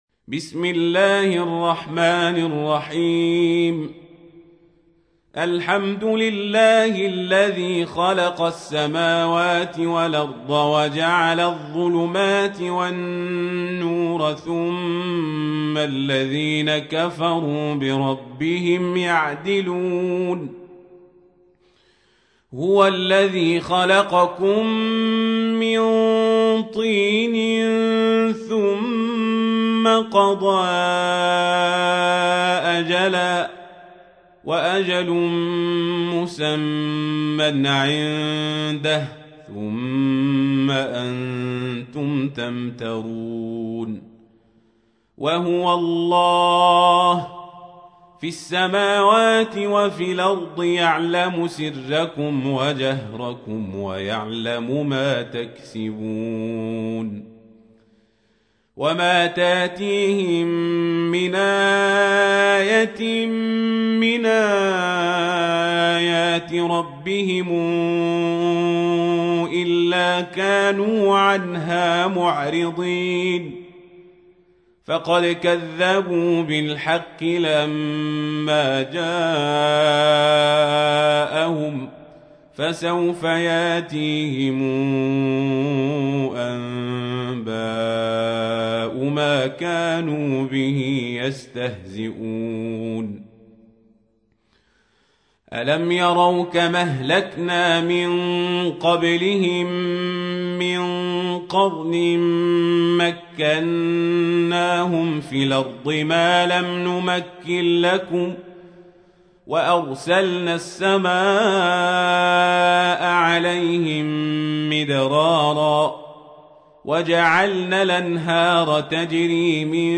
تحميل : 6. سورة الأنعام / القارئ القزابري / القرآن الكريم / موقع يا حسين